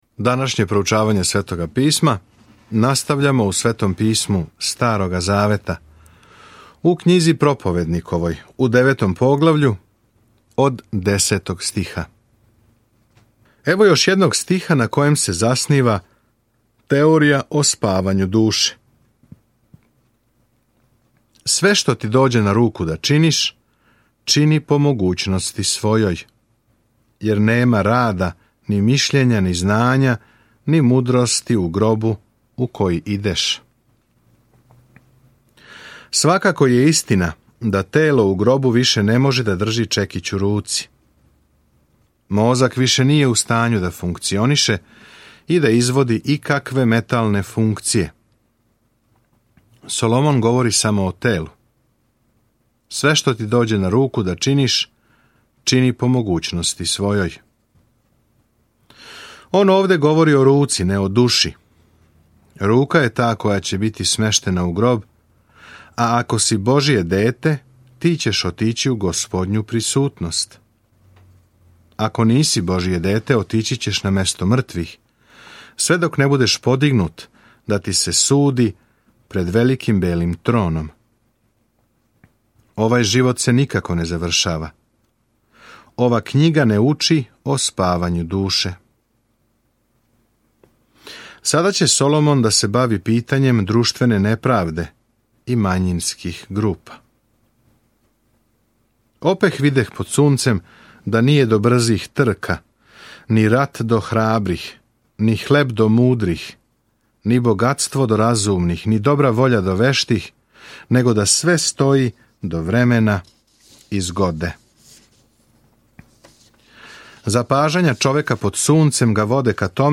Sveto Pismo Knjiga propovednikova 9:6-18 Knjiga propovednikova 10:1-5 Dan 8 Započni ovaj plan Dan 10 O ovom planu Проповедник је драматична аутобиографија Соломоновог живота када је покушавао да буде срећан без Бога. Свакодневно путујући кроз Проповедник слушате аудио студију и читате одабране стихове из Божје речи.